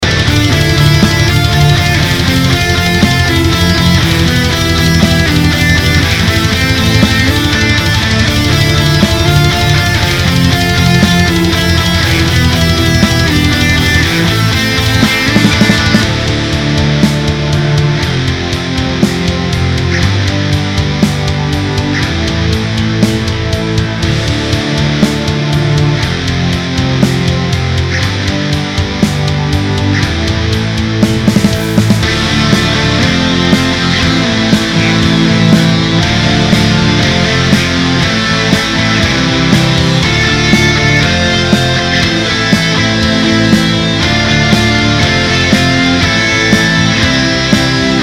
����� ������ � �������� �������� (������� melodic death metal �����).